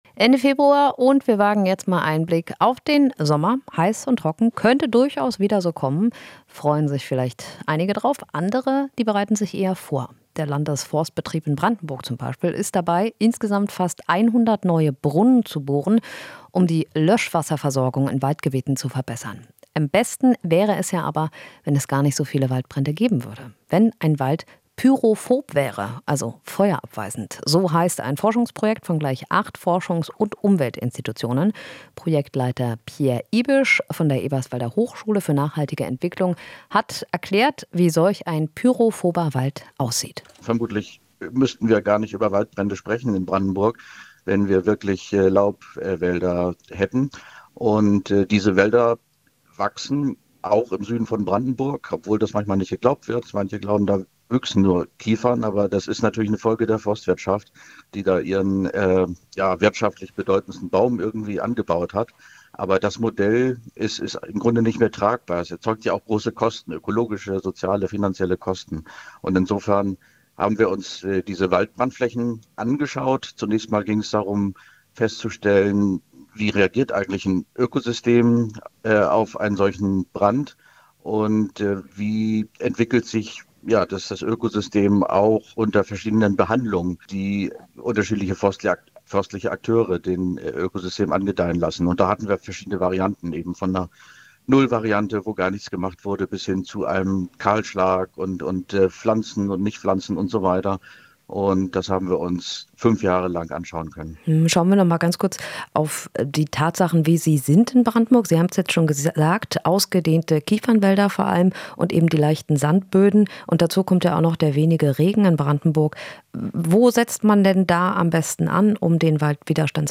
Interview - Forschungsprojekt: Wie können wir uns vor Waldbränden schützen?